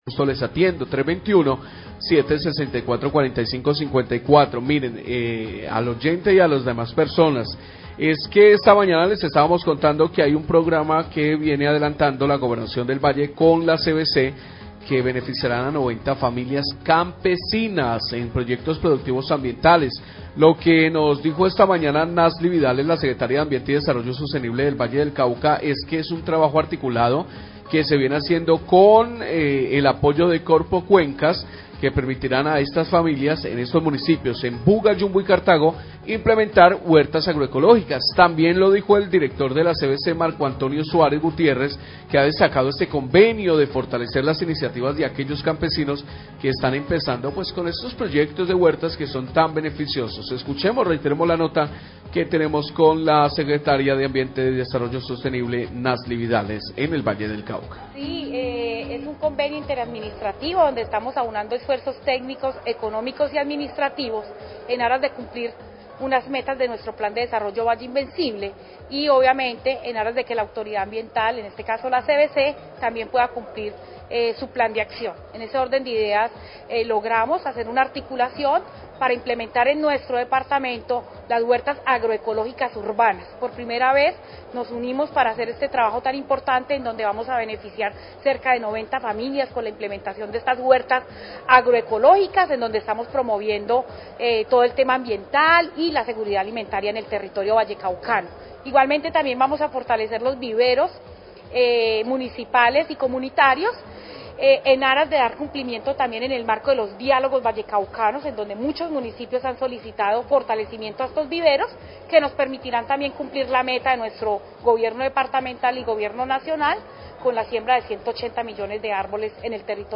Radio
La CVC, la Gobernación del Valle y Corpocuencas unieron esfuerzos para la implementación de huertas agroecológicas en los municipios de Buga, Yumbo y Cartago. Declaraciones de la Secretaria de Ambiente y Desarrollo Sostenible del Valle, Nazly Vidales.